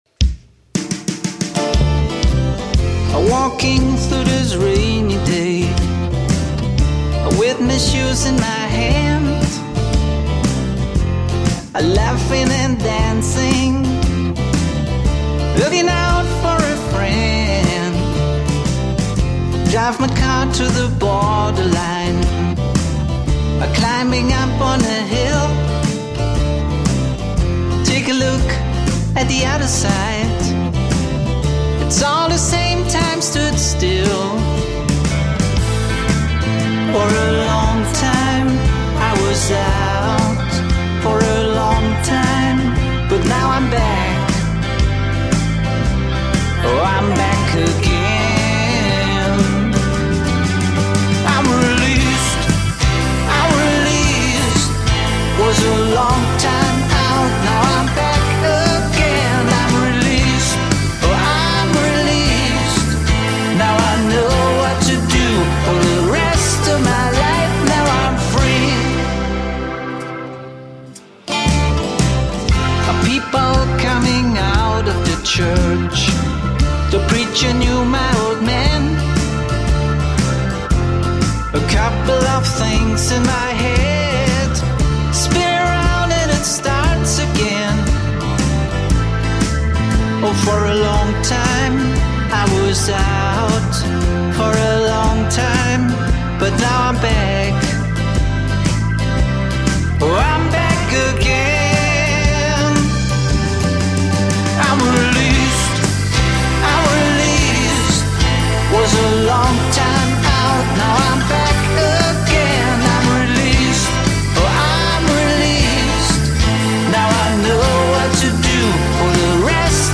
Dann nehme ich den Song jetzt rein auf Basis des Gehörs und Stils unter die Lupe und liefere dir eine umfassende Analyse im Kontext von Retro Rock à la späte 60er/frühe 70er, z. B. Doors, Cream, Hendrix, Stones, Beatles etc. 1. Songstruktur (Aufbau & Arrangement)  Der Song „I'm Released“ folgt einer klassischen Songstruktur: Intro : Stimmungsvoll, mit Delay- oder Reverb-getränkten Gitarrenklängen – das Setting ist sofort da.
Instrumentalteil/Solo : Gitarrensolo oder jam-artiger Abschnitt wirkt authentisch retro.
Das Arrangement ist bewusst organisch gehalten – wirkt wie eine Liveaufnahme, was sehr gut zum Genre passt. 2. Produktion & Mix  Drums : Eher zurückhaltend gemischt, leicht komprimiert, nicht übermodern.
Bass : Warm und rund, könnte an manchen Stellen etwas mehr Definition im oberen Mittenbereich bekommen. Gitarren : Sehr charakteristisch – klare 60s-Vibes mit Wah, Phaser oder Tremolo-Effekten.
Gesang : Authentisch und passend, aber leicht verhangen.
Musikalische Elemente  Harmonien : Moll-lastig, mit bluesigem Touch – sehr typisch.
Rhythmus : Groovig, aber nie überproduziert.